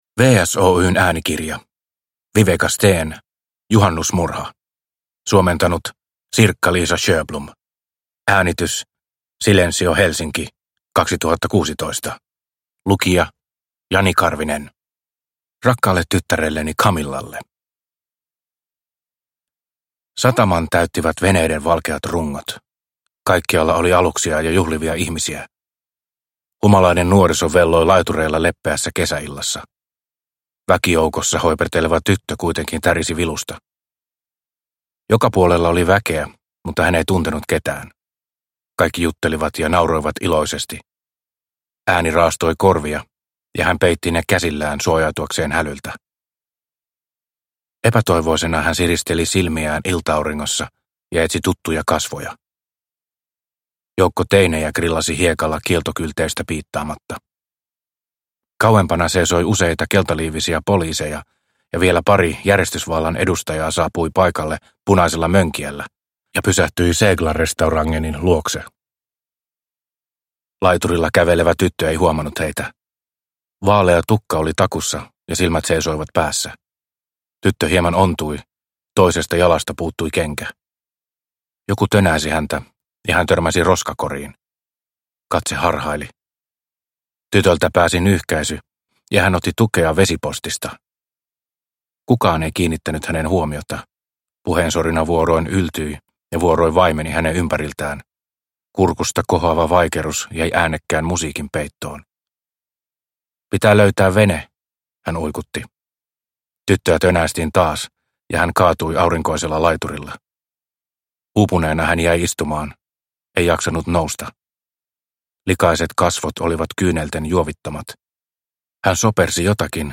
Juhannusmurha – Ljudbok – Laddas ner